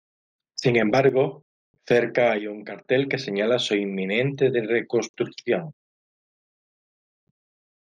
Uitgespreek as (IPA) /immiˈnente/